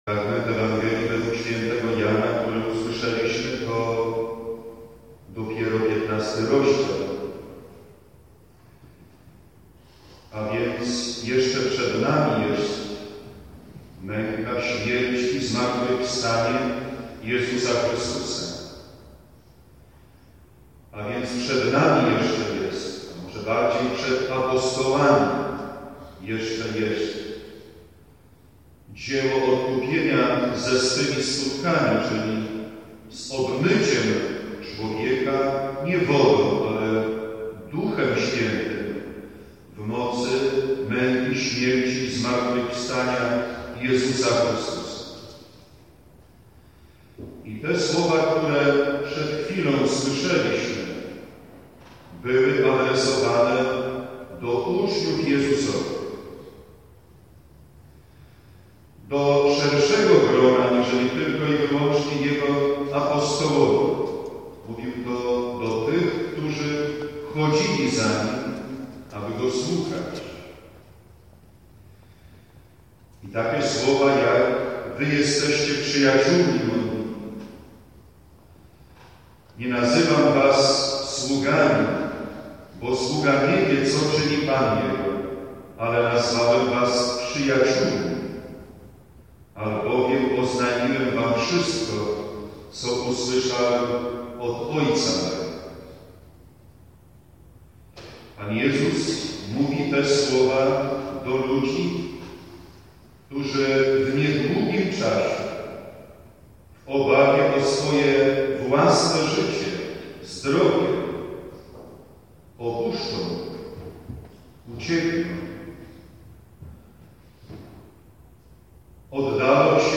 Msza św. – homilia